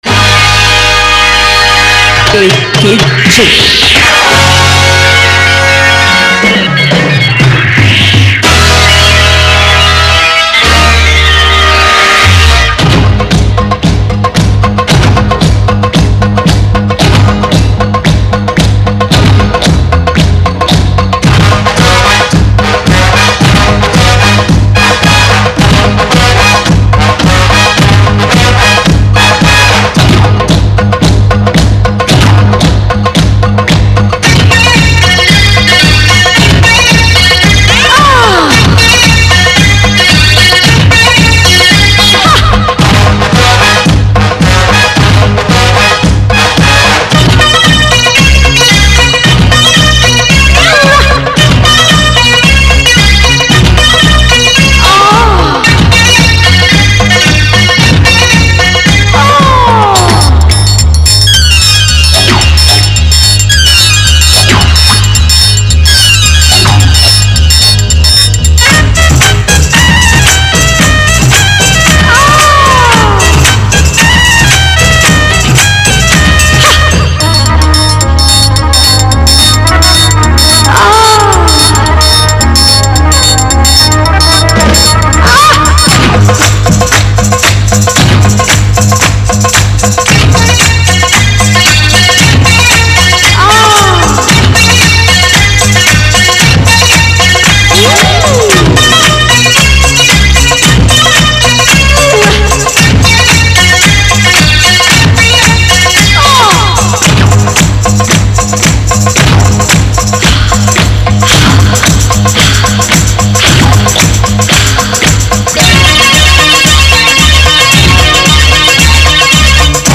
Mela Competition Filter Song